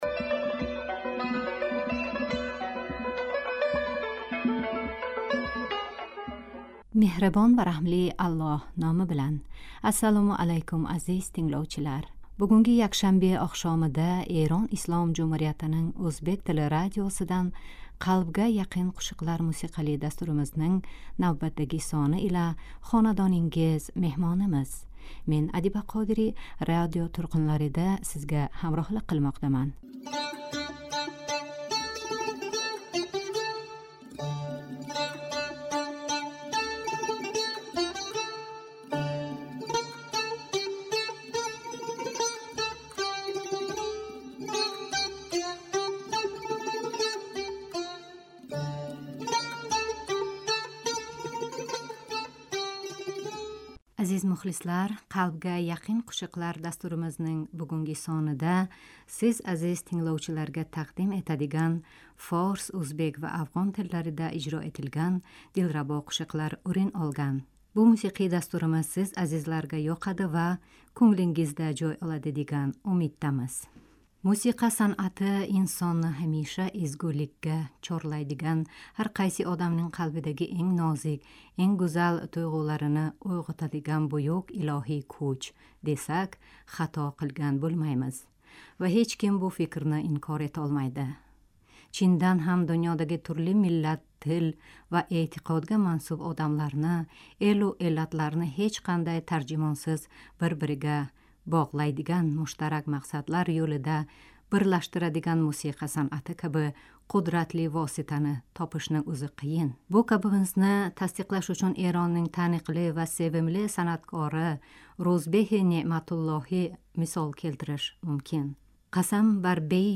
Азизлар, "Қалбга яқин қўшиқлар" дастуримизнинг бугунги сонида сиз азиз тингловчиларга тақдим этадиган форс,ўзбек, ва афғон тилларида ижро этилган дилрабо қўшиқлар ўрин олган.